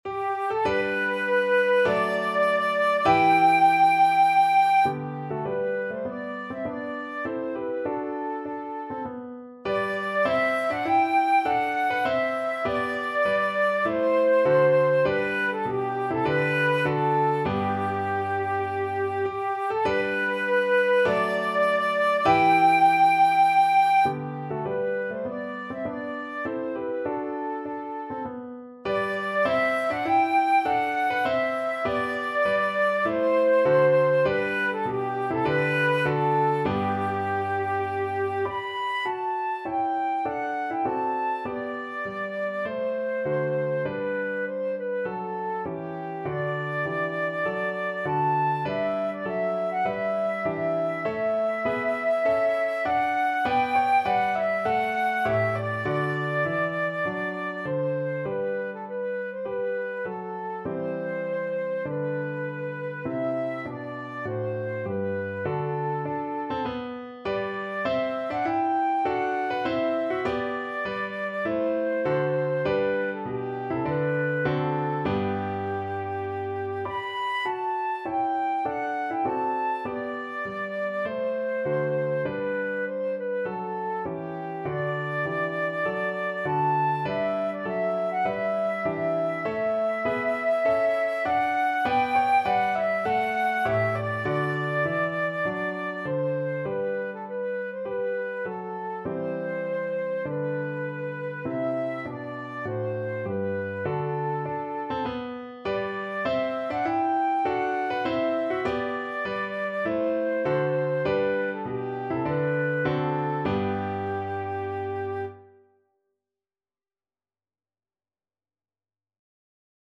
4/4 (View more 4/4 Music)
Classical (View more Classical Flute Music)